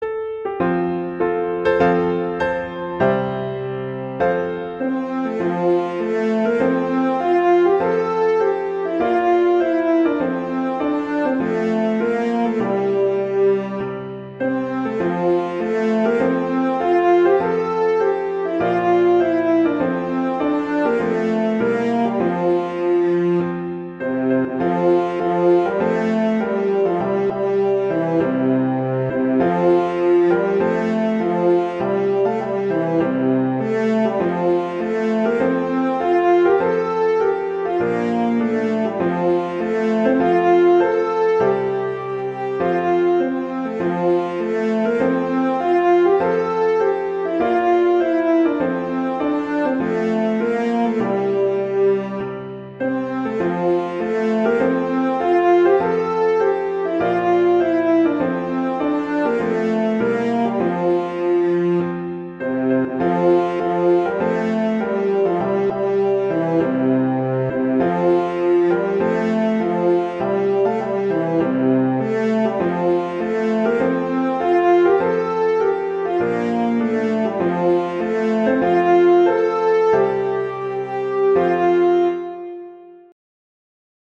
traditional, irish, children
F major
♩=100 BPM
Pretty tune but not a duet - horn doubles the piano melody.